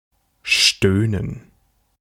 Ääntäminen
IPA: /ʁɑ.le/